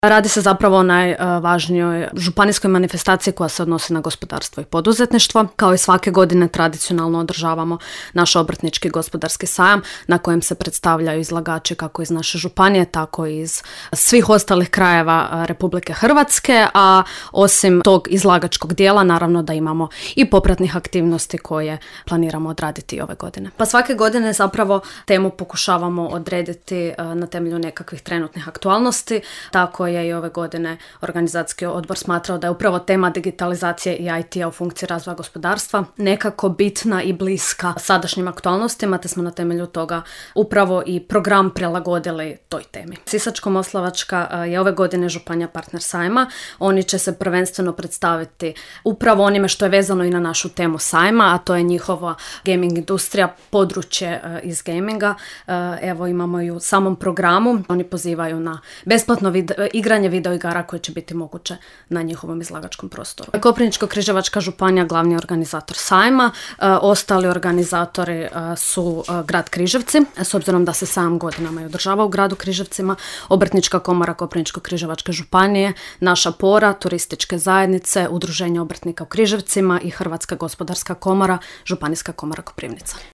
rekla je u emisiji Aktualnosti iz županije